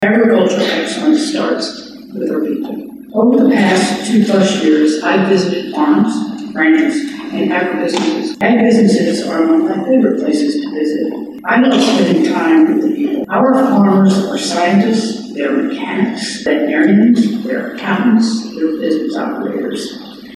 The Kansas Farm Bureau hosted its annual meeting Sunday, with Gov. Laura Kelly as the key guest speaker.